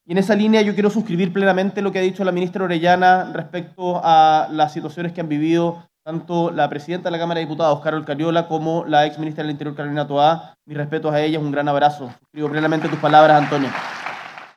Así lo señalaron ambas autoridades de Gobierno en medio de una actividad realizada en la comuna de Quinta Normal.